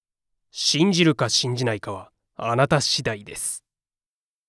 ミーム・ネタ系ボイス素材　2
SNS・投稿サイト・バラエティ番組等で見かけるあれやこれやのネタ・コラ画像等フレーズの声素材